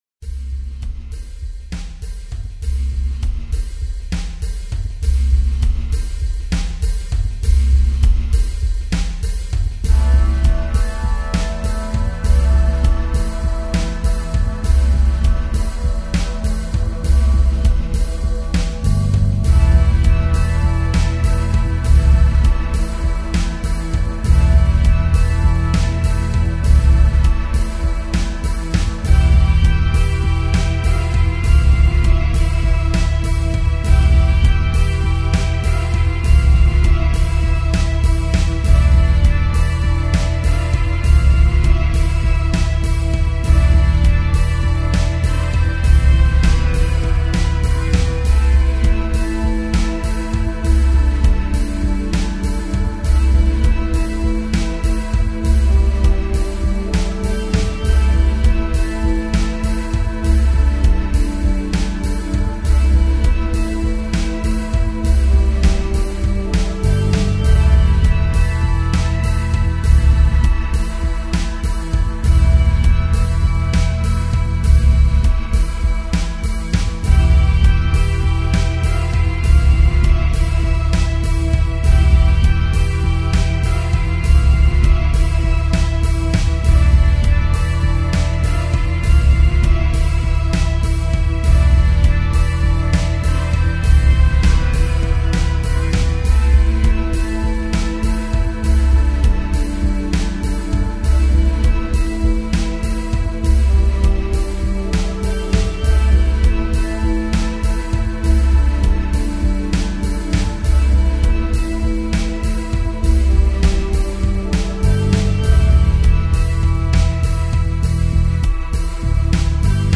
[Lead Git Soundfont Demo :]